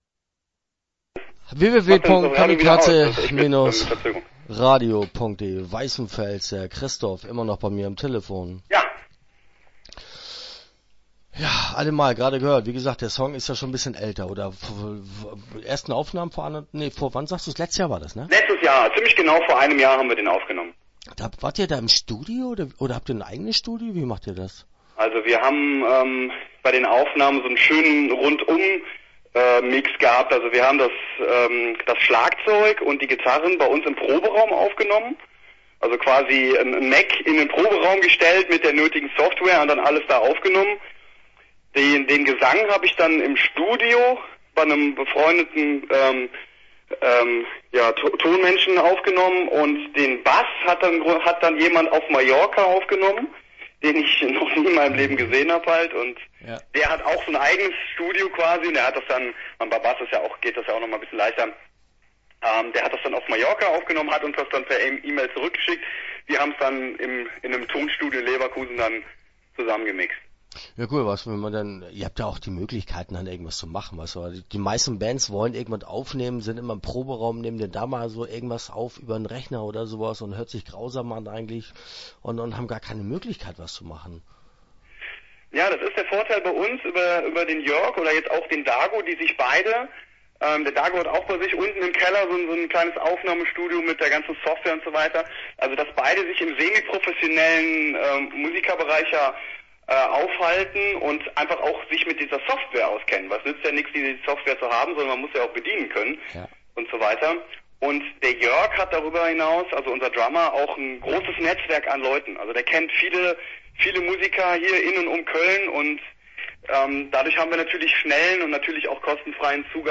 Start » Interviews » Weißenfels